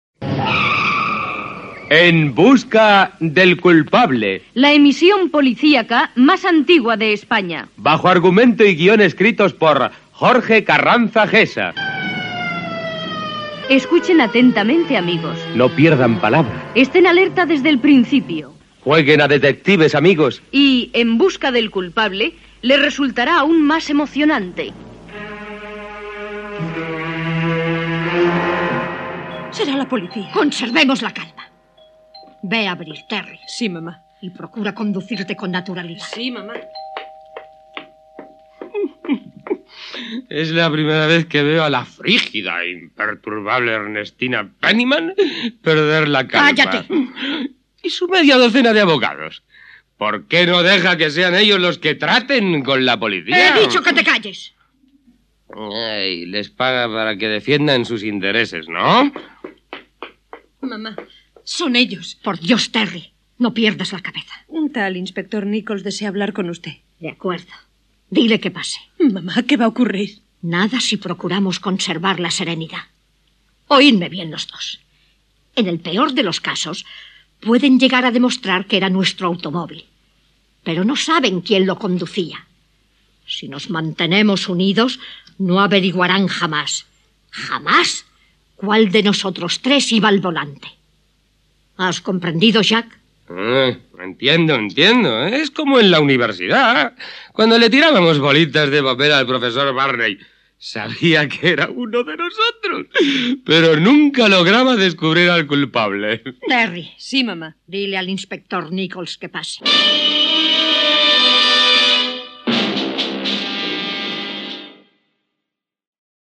Careta del programa, primera escena, amb la discussió entre els personatges i la trama que construeixen abans de l'arribada de la policia
Ficció